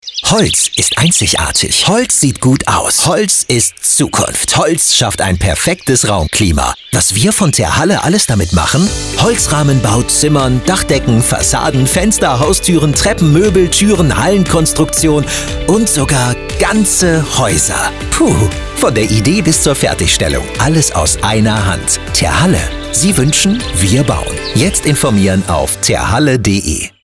Radiospot „Terhalle – Sie wünschen wir bauen“
Radiospot-Terhalle-Sie-wünschen-wir-bauen-1.mp3